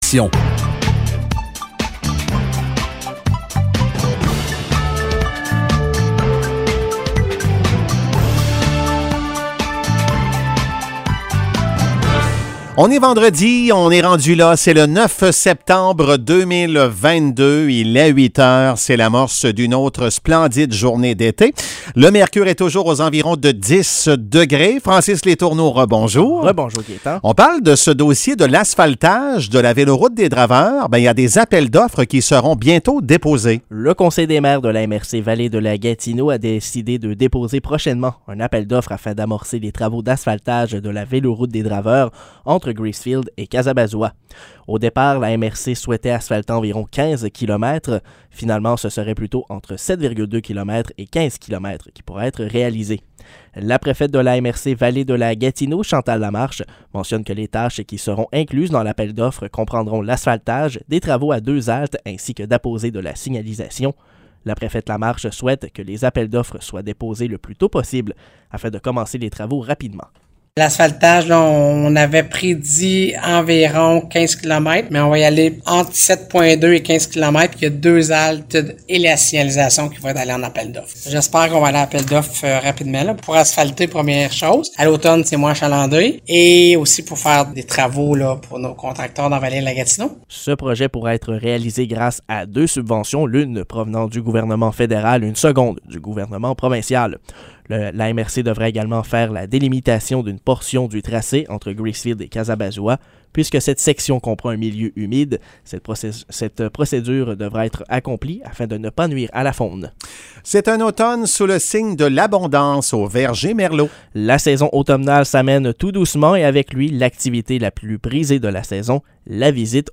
Nouvelles locales - 9 septembre 2022 - 8 h